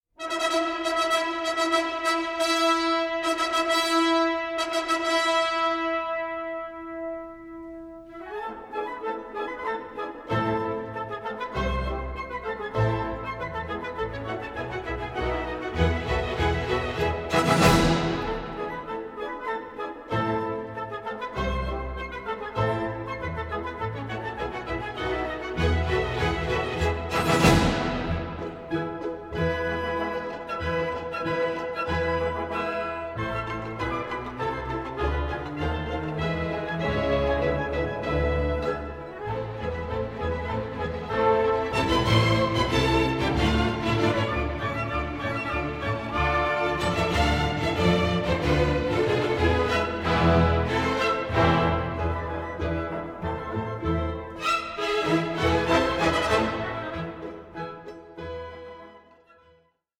Légende dramatique en quatre parties
soprano
tenor
bass